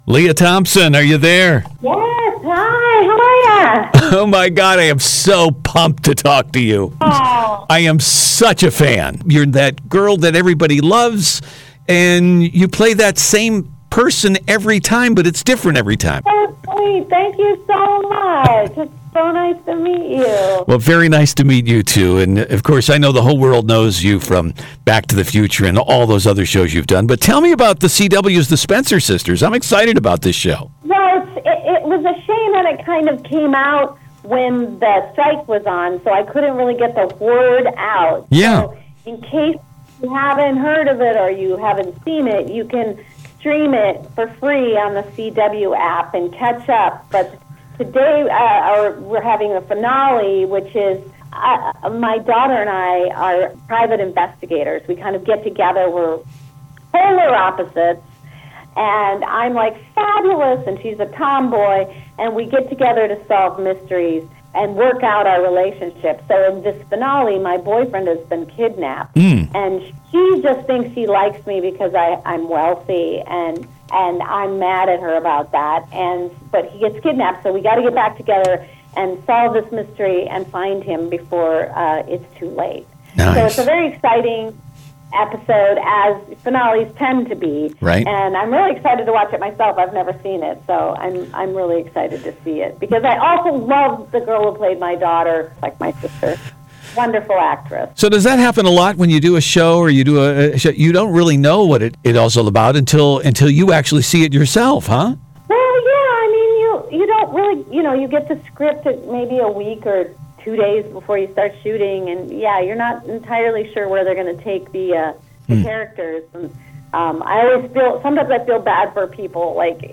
LEAH THOMPSON – FULL INTERVIEW https